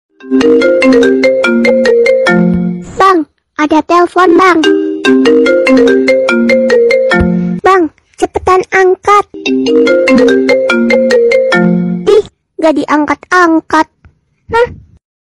Kategori: Nada dering
Ini adalah nada dering yang lucu dan sedang viral di TikTok.